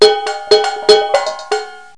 00085_Sound_043Drums.AIF